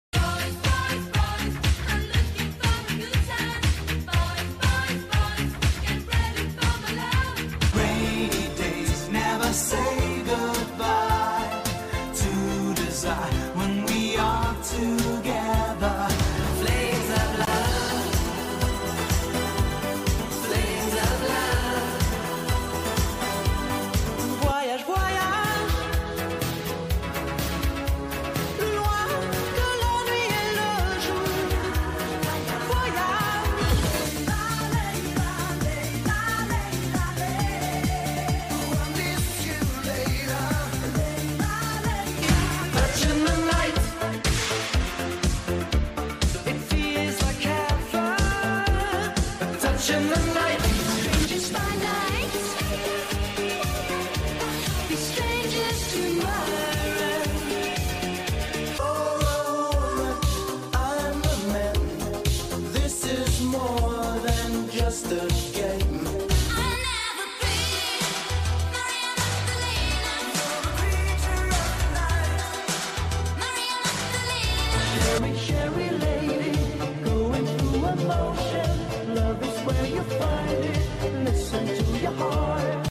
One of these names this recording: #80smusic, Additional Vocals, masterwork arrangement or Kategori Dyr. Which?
#80smusic